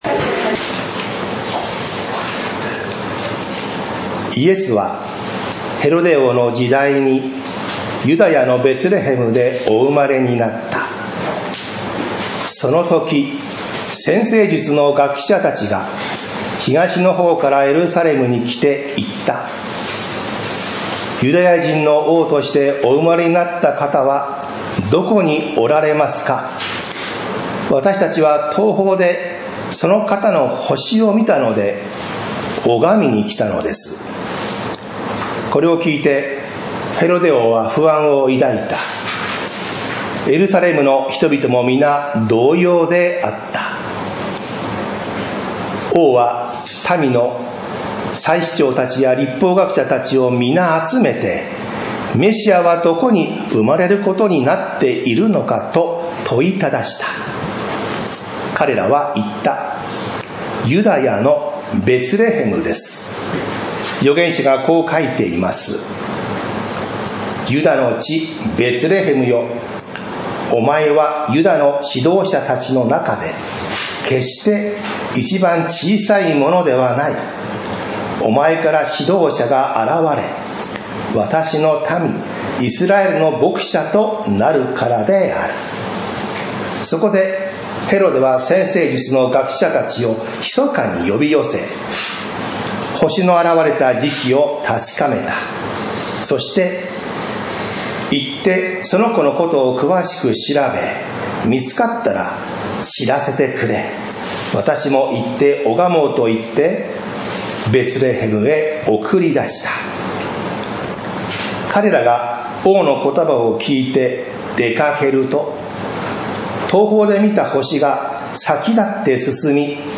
私たちは毎週日曜日10時30分から11時45分まで、神様に祈りと感謝をささげる礼拝を開いています。